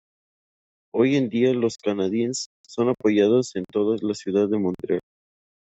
Pronounced as (IPA) /ˈtoda/